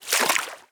Footstep_Water_02.wav